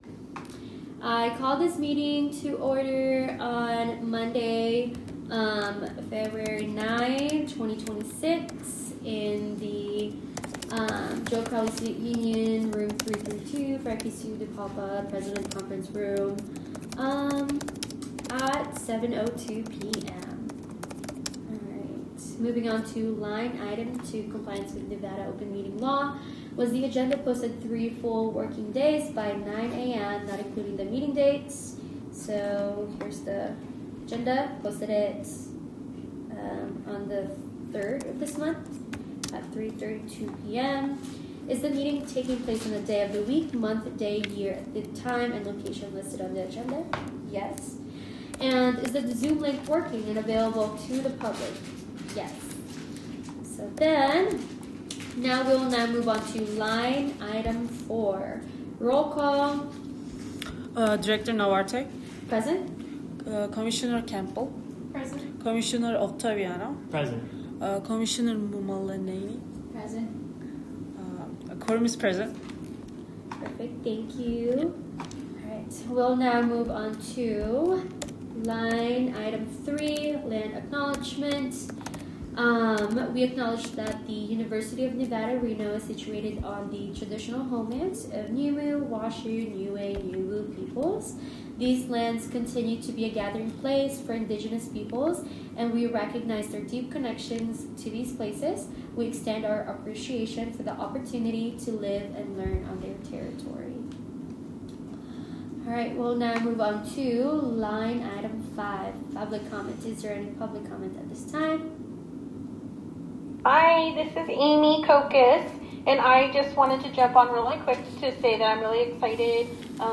Meeting Type : Diversity and Inclusion